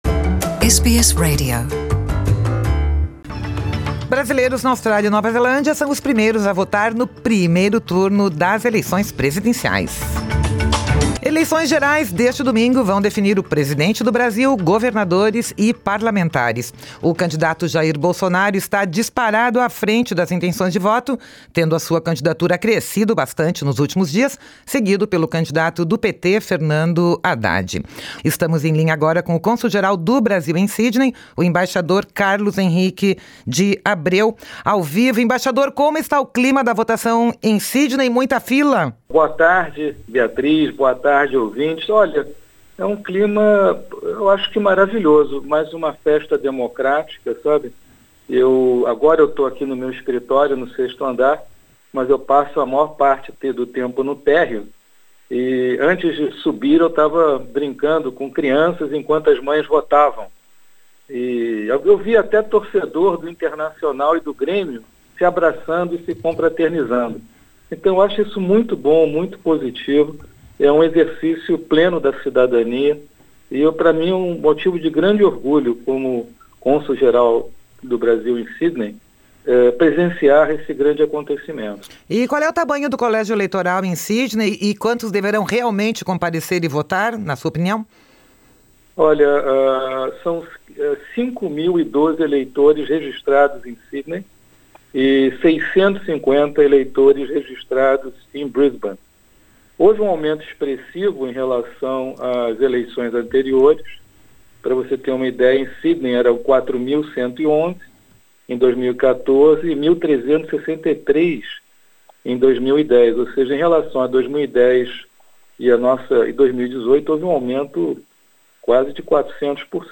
O clima da votação em Sydney é tranquilo e harmonioso, sem grandes filas, diz o embaixador Carlos Henrique de Abreu, Cônsul-Geral do Brasil na cidade, em entrevista ao Programa de Língua Portuguesa da Rádio SBS.